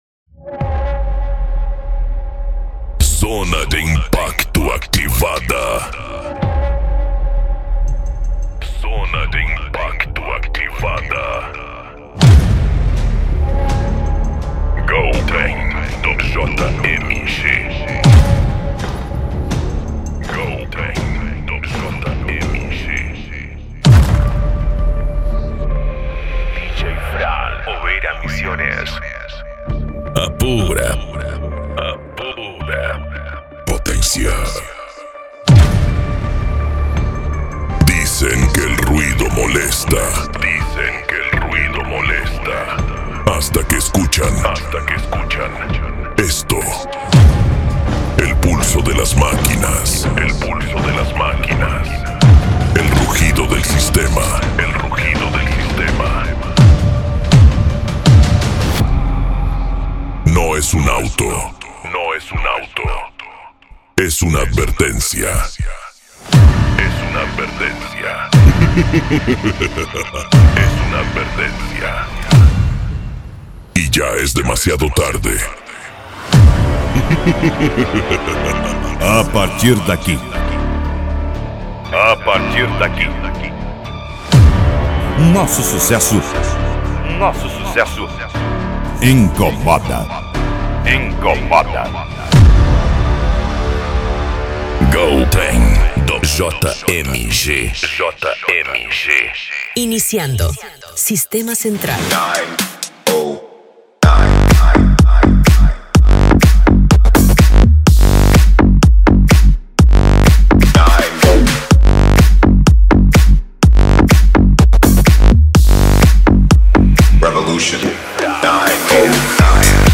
Bass
Cumbia
PANCADÃO
Remix